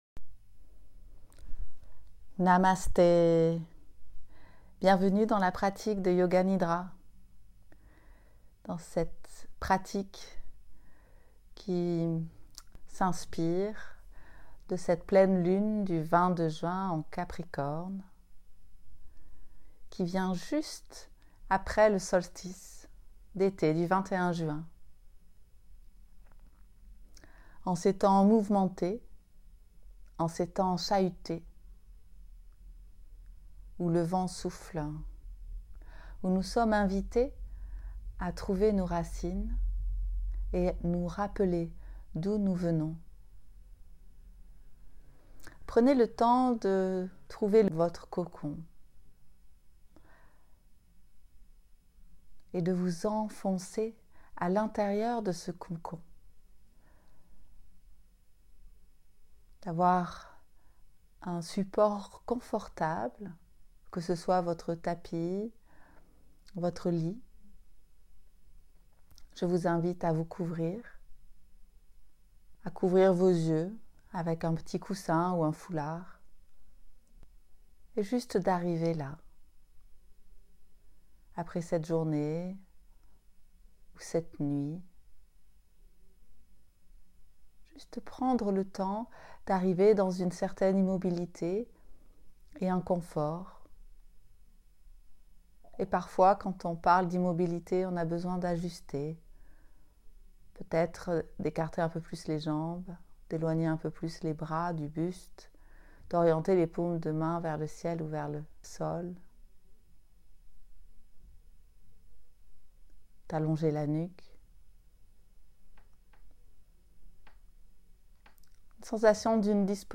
Les amis, comme chaque mois, voici le nidra de la pleine lune du 22/06.